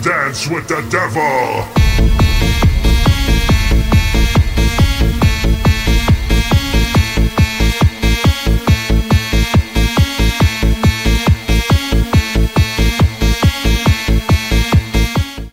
Kermis geluid Dance with the Devil
Categorie: Geluidseffecten
Deze onweerstaanbare kermisdeuntje brengt de echte sfeer van de kermis tot leven, en laat je terugdenken aan de leukste momenten van de Tilburgse Kermis.
geluidseffecten, kermis geluiden
kermis-geluid-dance-with-the-devil-nl-www_tiengdong_com.mp3